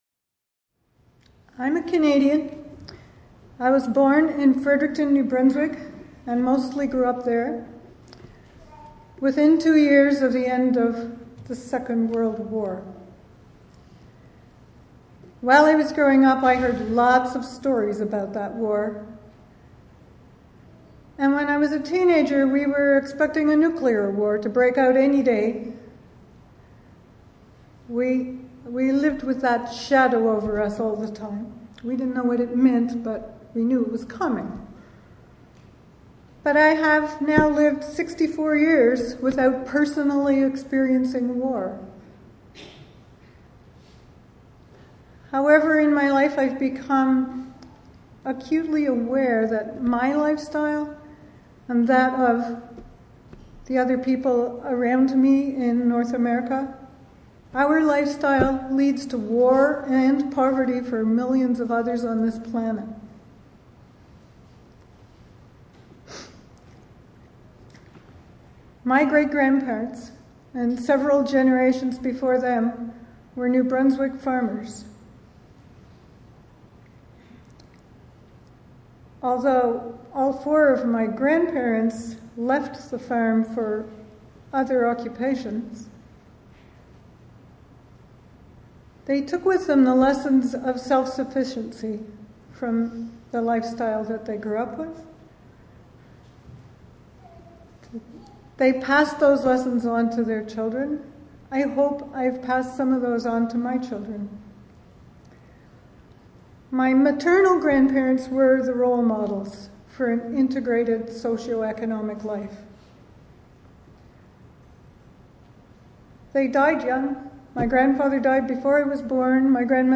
lecture
to a full house at the Fountain Performing Arts Centre at King’s Edgehill School